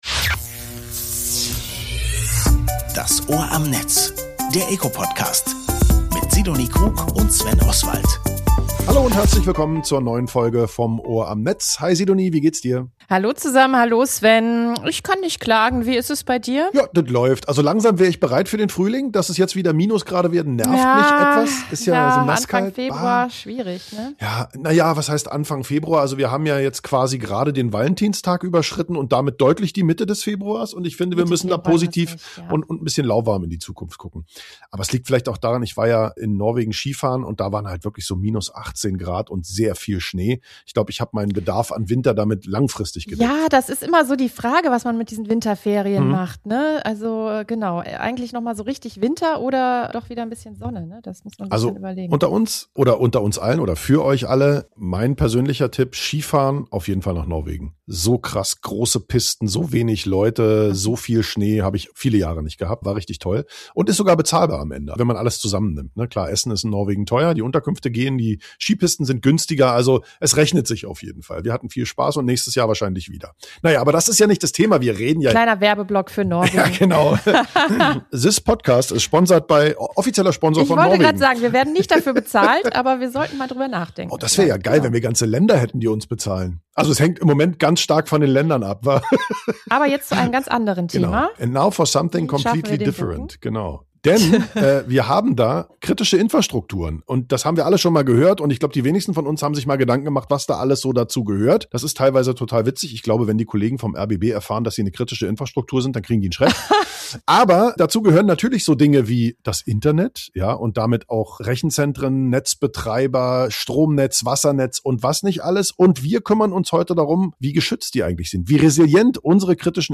Podcast Das Ohr am Netz: Alle 14 Tage sprechen wir im eco Podcast mit spannenden Expertinnen und Experten der digitalen Welt.